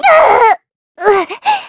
Xerah-Vox_Damage_kr_02.wav